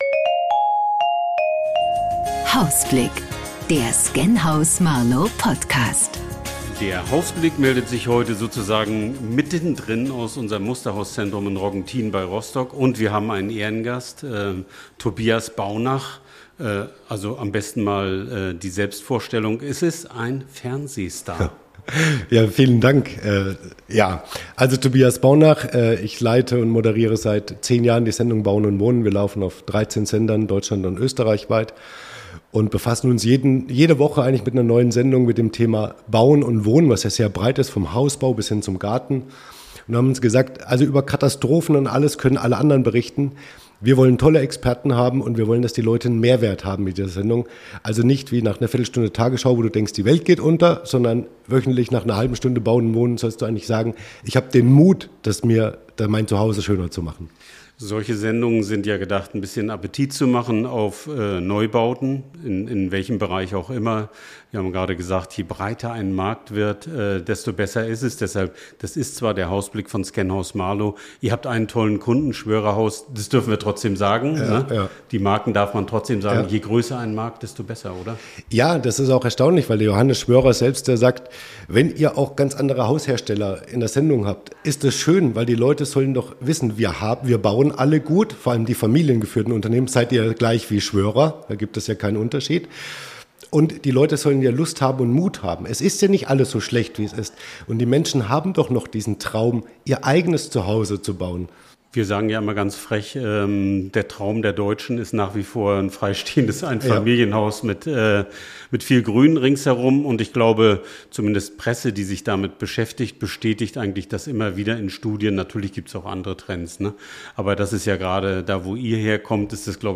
Live aus dem Musterhauscentrum Roggentin bei Rostock